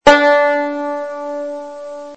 Звуки банджо
Одна струна